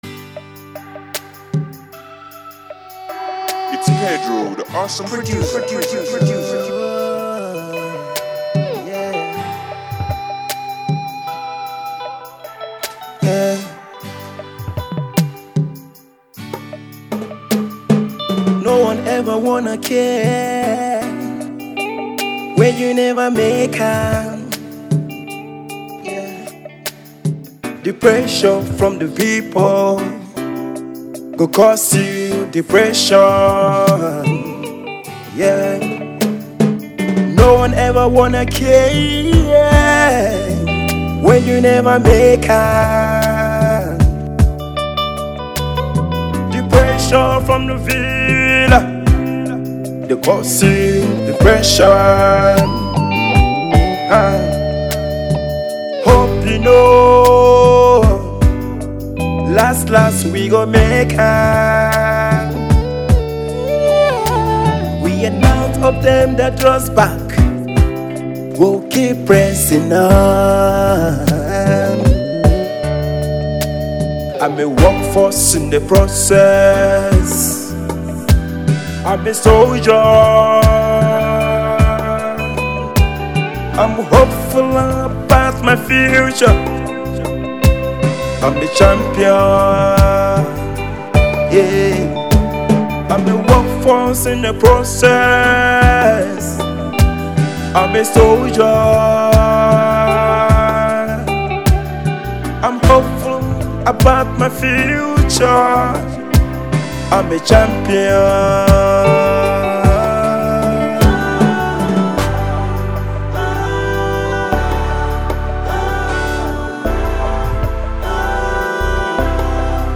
gospel
a song of hope and encouragement